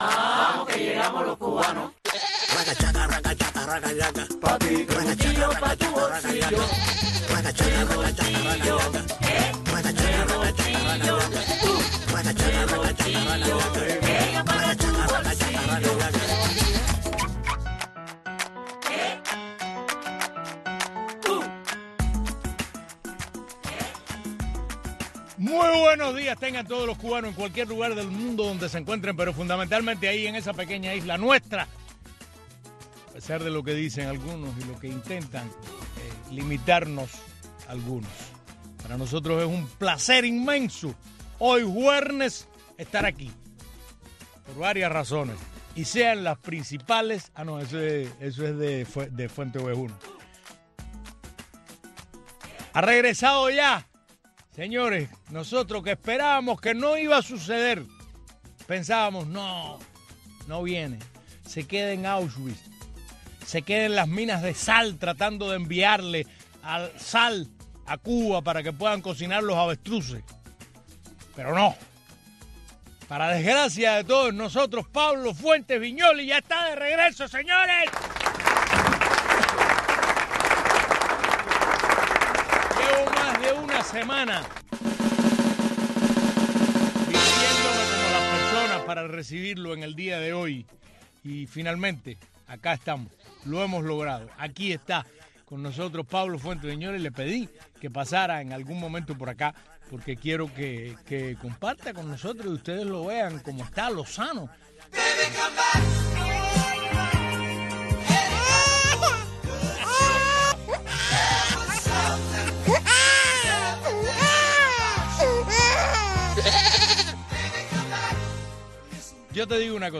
programa matutino